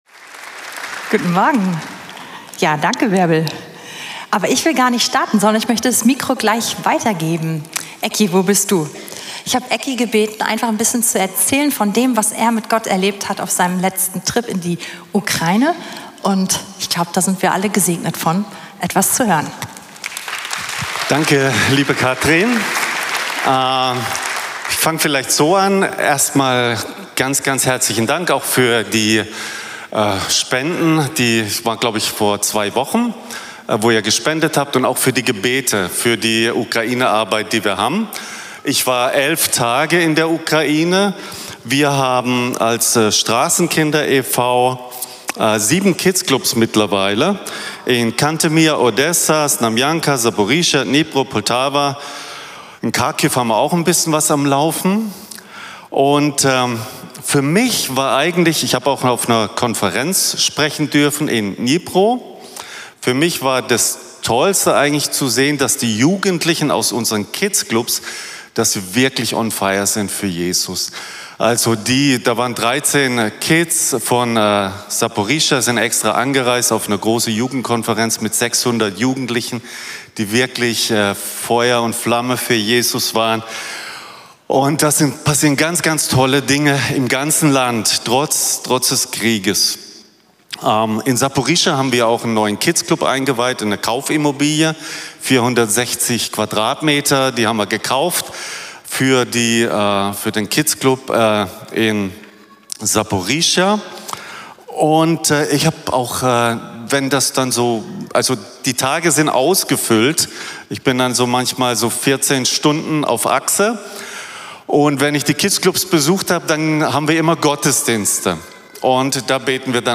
Predigten von Veranstaltungen der Gemeinde auf dem Weg, Berlin